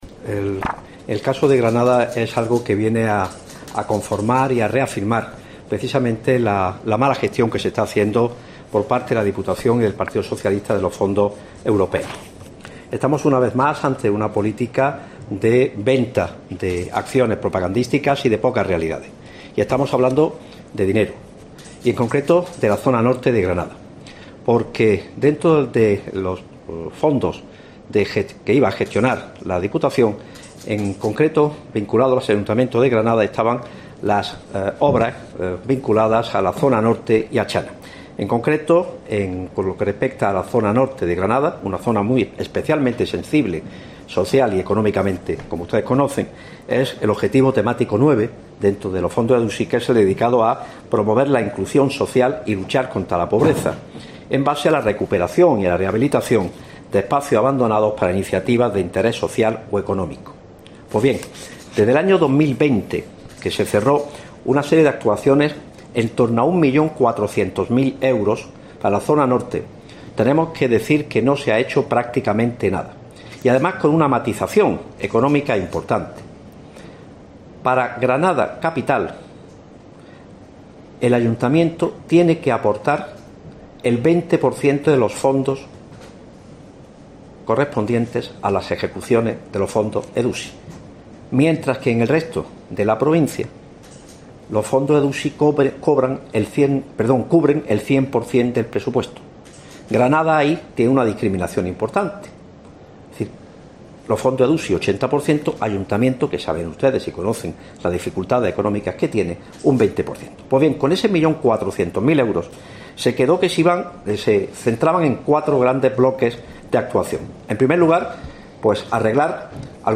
Así lo han manifestado los concejales del Ayuntamiento de Granada Luis González y Carlos Ruiz Cosano en una rueda de prensa conjunta con el diputado provincial Antonio Narváez, donde han denunciado la inacción de la gestión del PSOE tanto en el Consistorio como en la Diputación.